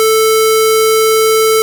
I genereated a 440 square wave via Audacity generate menu.
I played back the square wave previously generated in Audacity from another application and recorded it.
The recordings waveform is very distorted.
I have tried both WASAPI with loopback and also both MME and DirectSound with Stero Mixer.